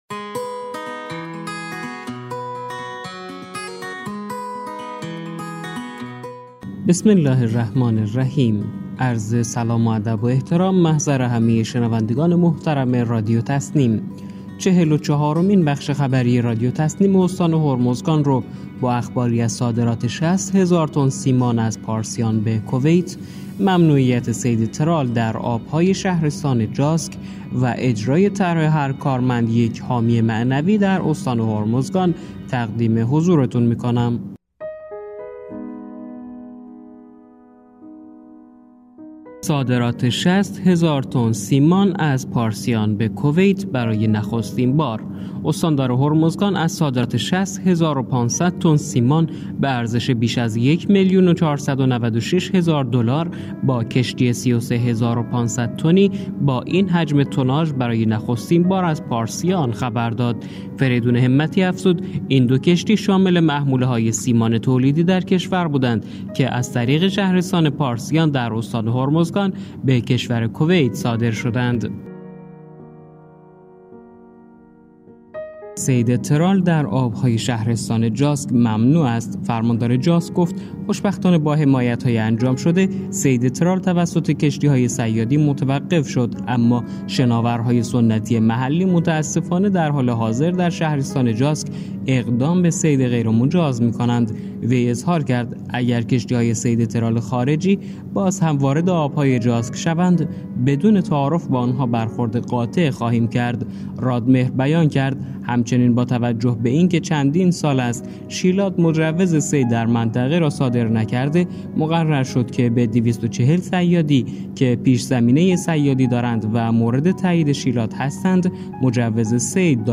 گروه استان‌ها- چهل و چهارمین بخش خبری رادیو تسنیم استان هرمزگان با بررسی مهم‌ترین اخبار این استان در 24 ساعت گذشته منتشر شد.